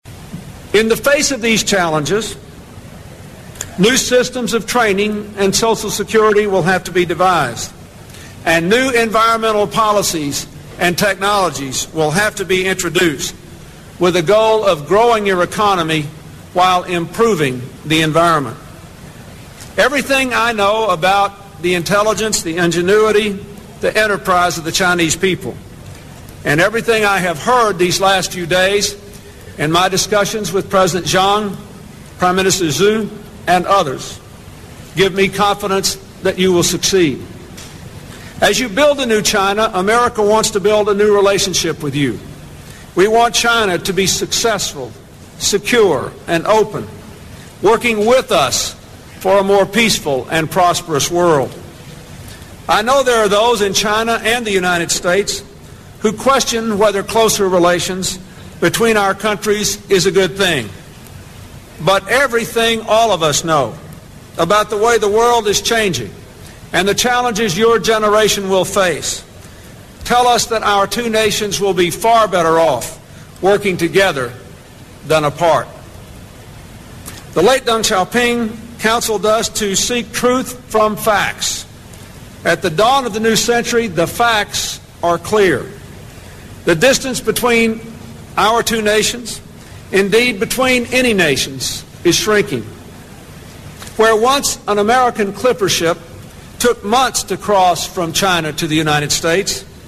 名校励志英语演讲 111:21世纪的挑战(5) 听力文件下载—在线英语听力室
借音频听演讲，感受现场的气氛，聆听名人之声，感悟世界级人物送给大学毕业生的成功忠告。